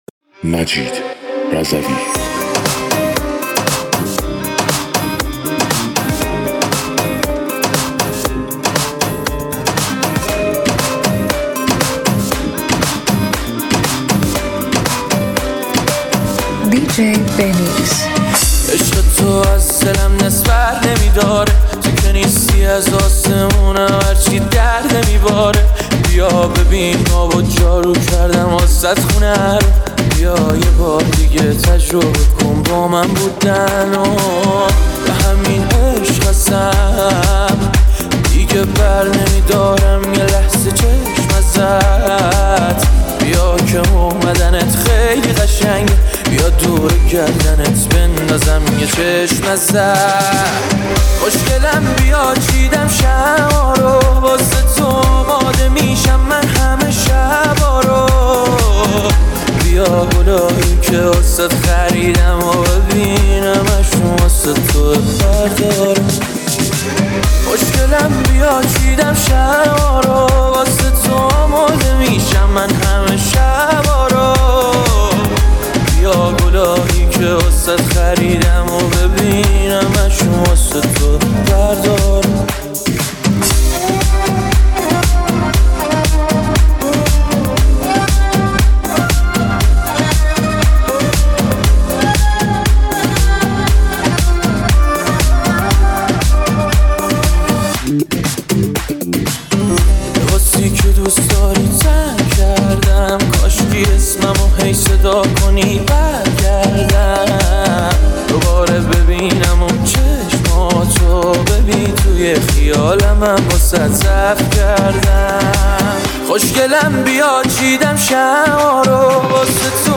آماده‌اید برای یک سفر موسیقایی پر از انرژی؟